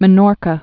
(mə-nôrkə, mĕ-nôrkä)